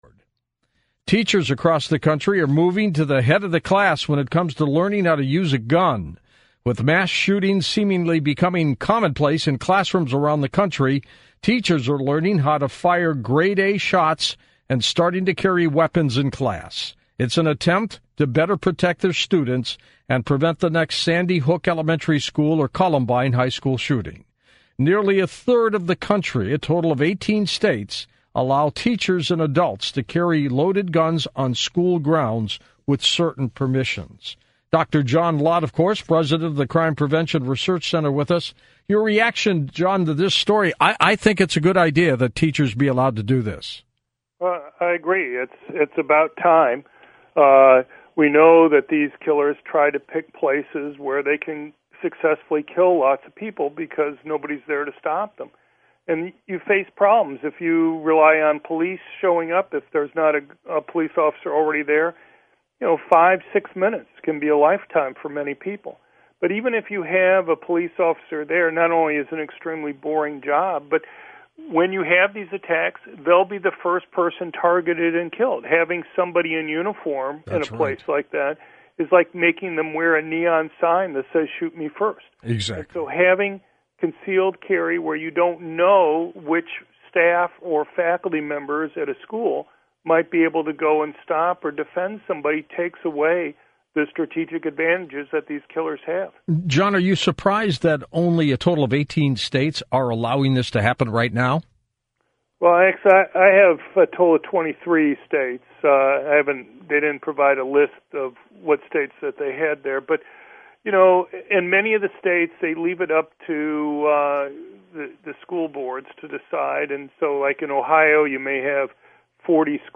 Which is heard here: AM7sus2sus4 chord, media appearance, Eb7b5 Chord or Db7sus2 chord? media appearance